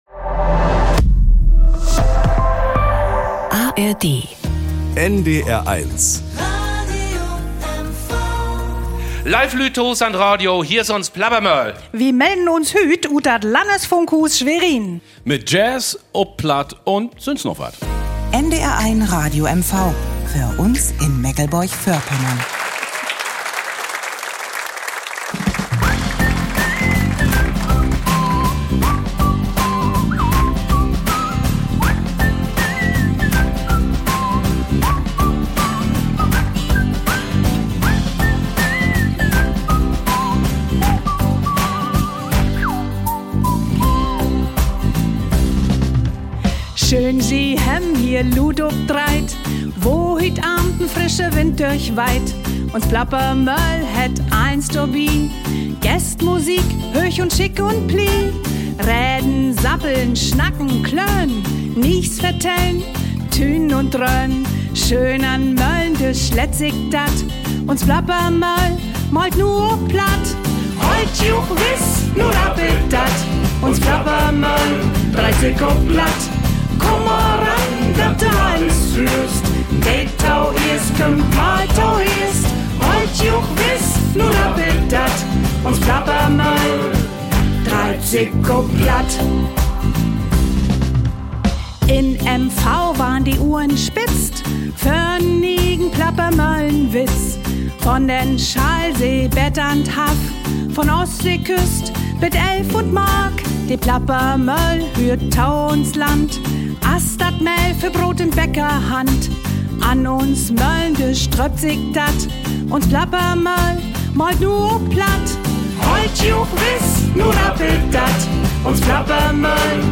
Die NDR-Funkhausband legt ein grooviges Fundament für eine Uraufführung: Jazzklassiker mit neuen, plattdeutschen Texten präsentieren Yared Dibaba
Die Plappermoehl Spezial wurde aufgezeichnet am 12. März bei einem exklusiven Funkhauskonzert im NDR-Landesfunkhaus in Schwerin.